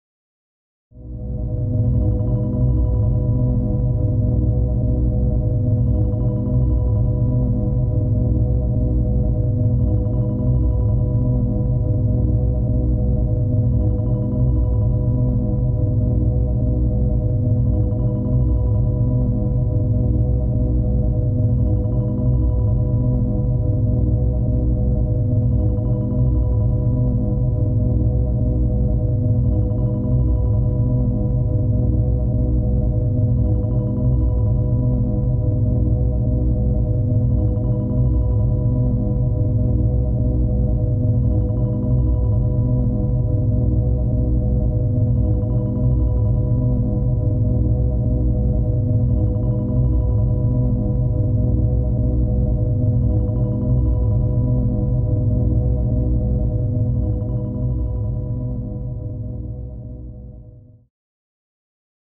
skins/Skins/Yume-Nikki/applause.wav
applause.wav